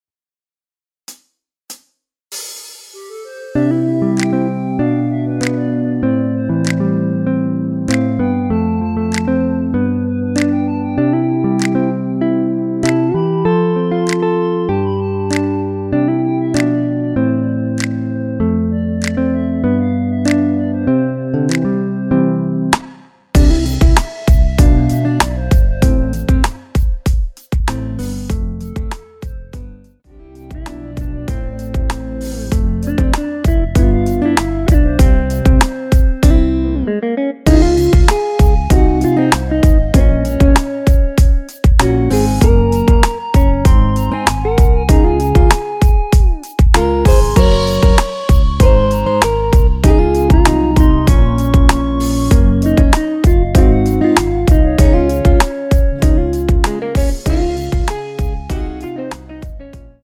키 C 가수